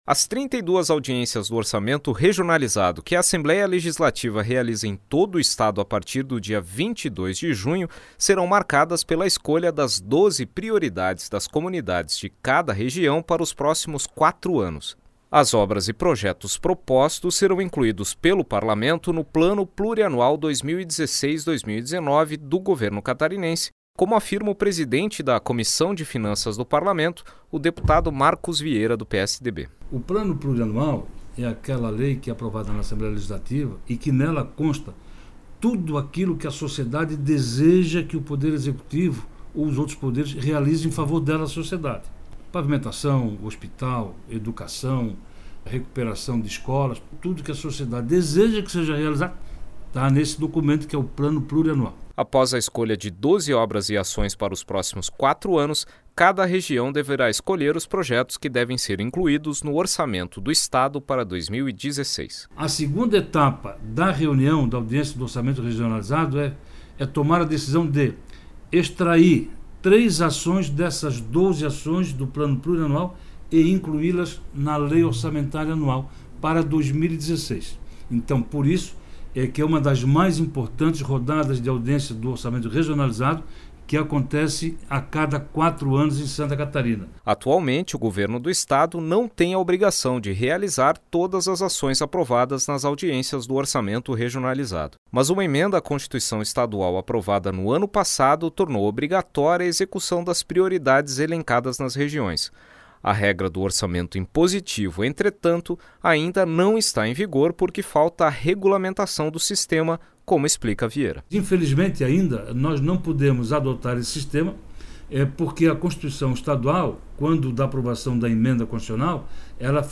Entrevistas com: deputado Marcos Vieira (PSDB), presidente da Comissão de Finanças e Tributação.